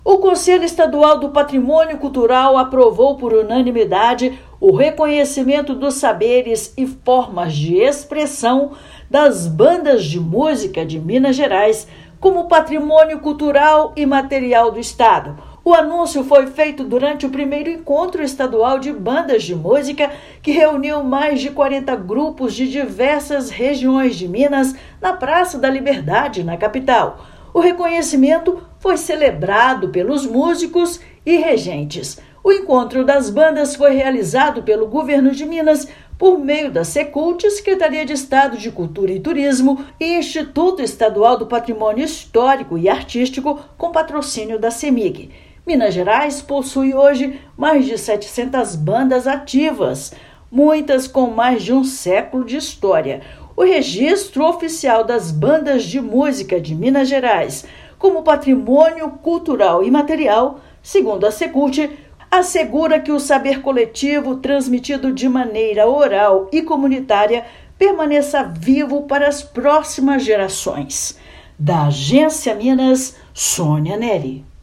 Anúncio foi feito durante o Encontro Estadual de Bandas, reunindo mais de 40 corporações musicais na Praça da Liberdade. Ouça matéria de rádio.